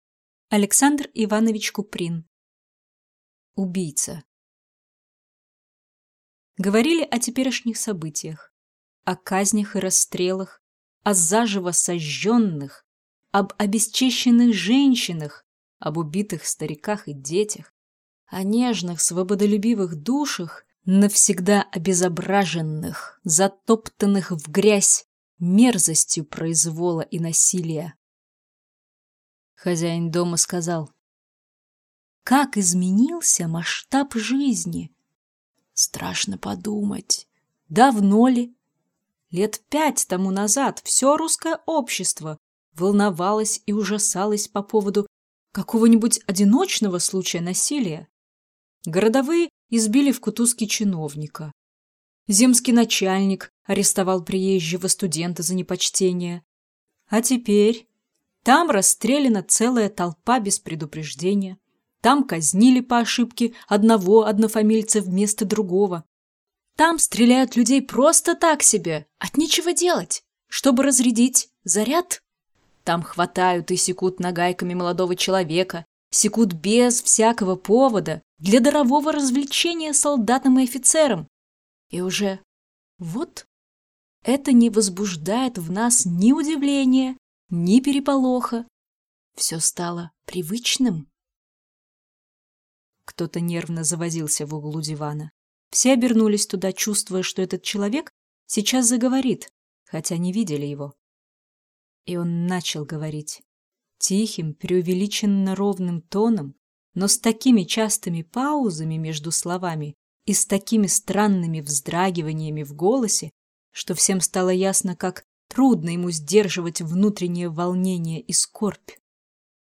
Аудиокнига Убийца | Библиотека аудиокниг